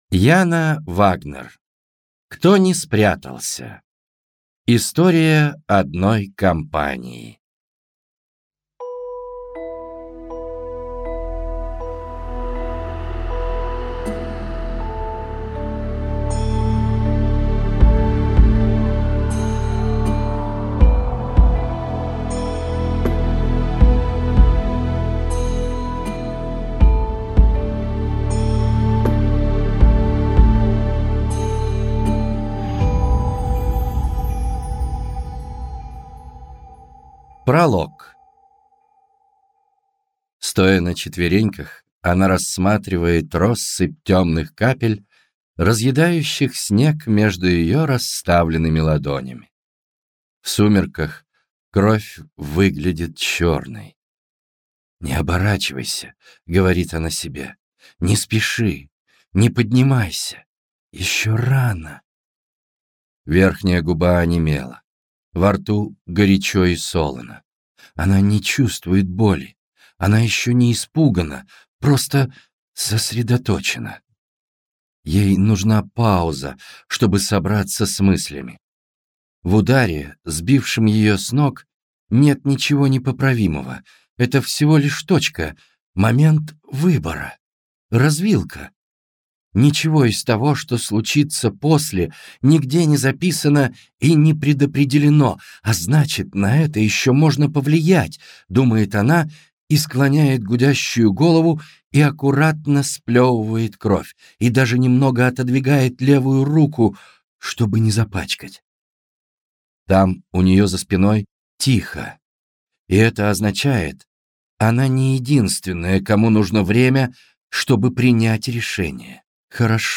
Аудиокнига Кто не спрятался.